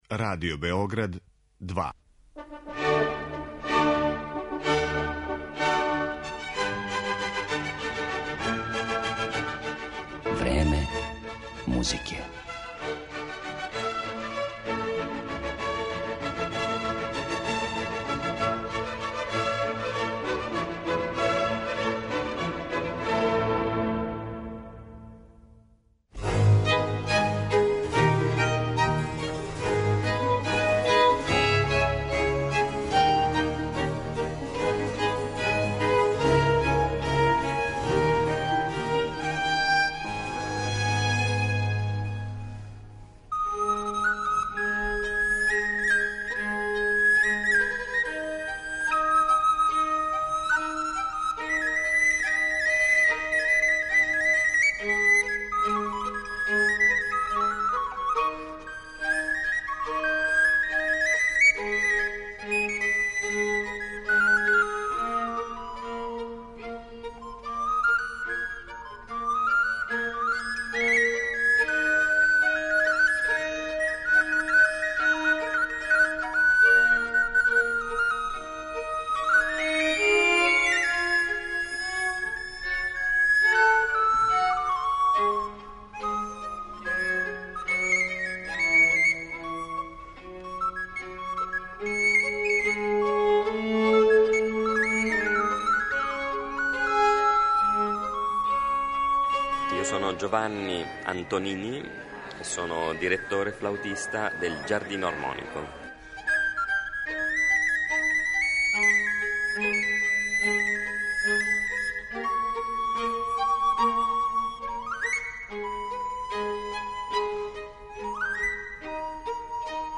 Данашња емисија посвећена је једном од најзанимљивијих барокних ансамбала у Европи и саставу који је неколико пута гостовао и код нас - Giardino Armonico. Моћи ћете да чујете и интервју са његовим руководиоцем и солистом на бројним врстама старе флауте Ђованијем Антонинијем. Ови изврсни музичари изводиће дела Тарквина Мерула, Антонија Вивалдија, Георга Филипа Телемана и Јохана Хајнриха Шмелцера.